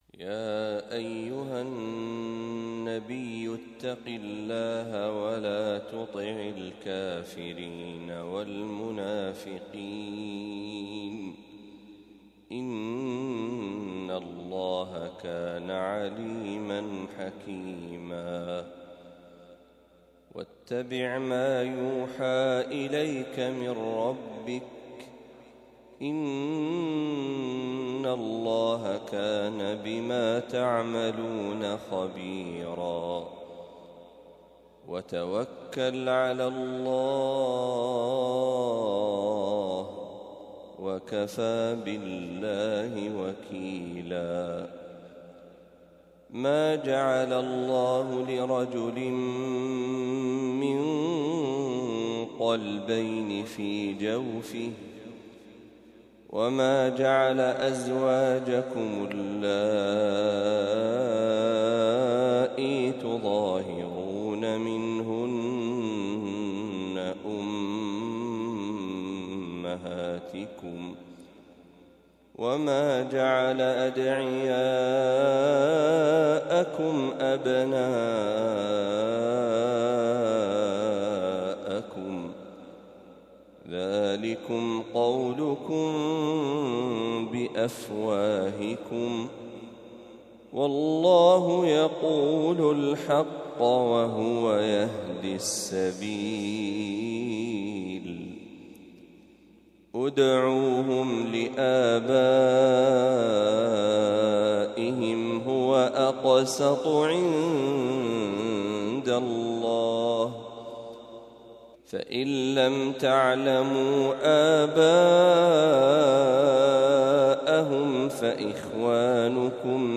فواتح سورة الأحزاب | فجر الأحد ٢١ صفر ١٤٤٦هـ > 1446هـ > تلاوات الشيخ محمد برهجي > المزيد - تلاوات الحرمين